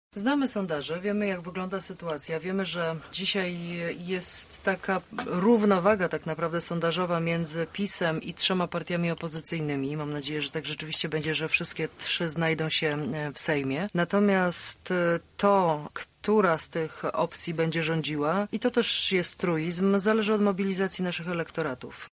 Posłanka Platformy Obywatelskiej, będąca jednocześnie „jedynką” listy Koalicji Obywatelskiej w naszym regionie była gościem Porannej Rozmowy Radia Centrum.